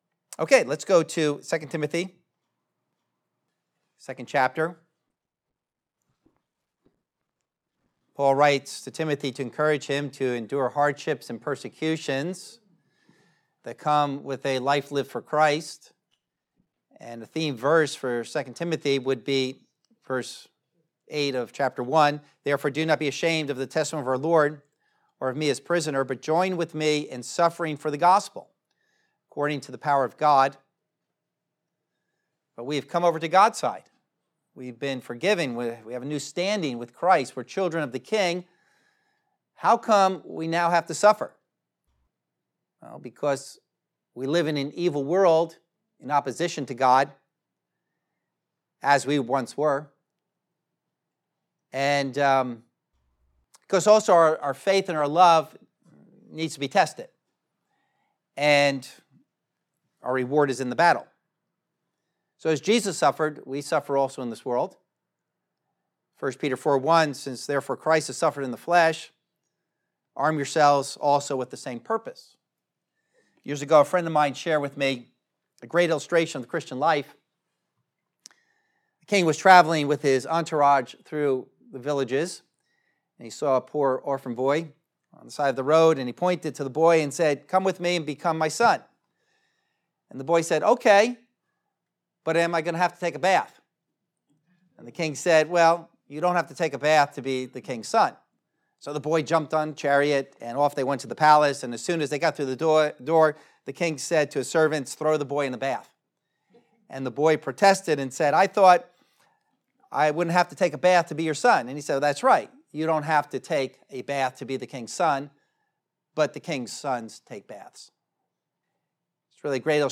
2 Timothy Series #4 - 2:1-10 - The Teacher, Soldier, Athlete, & Farmer | Message | Harbor Bible Church